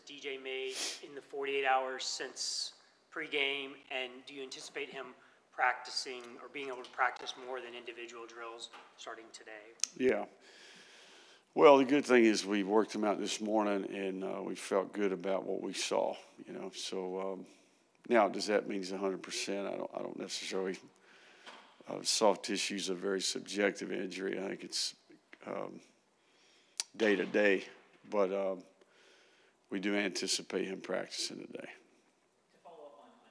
Napier said at Monday’s news conference he expected Lagway to practice Monday: